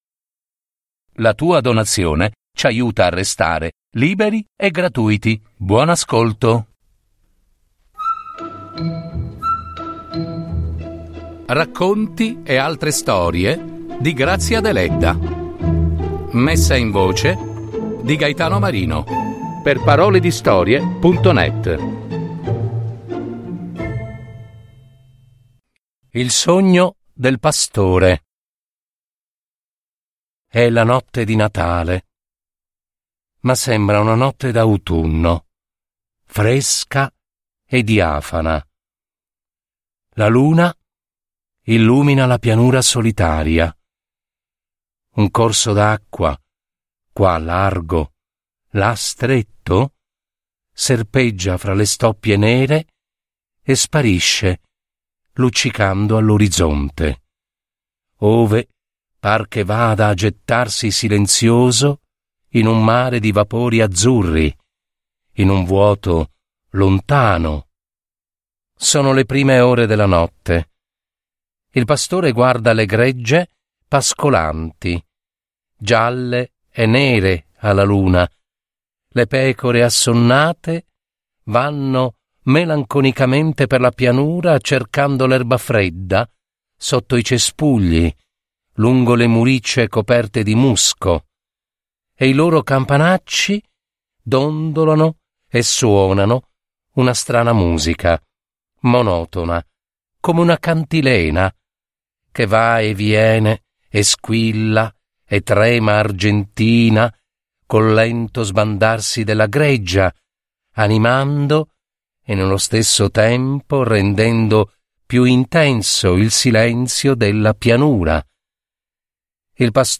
Messa in voce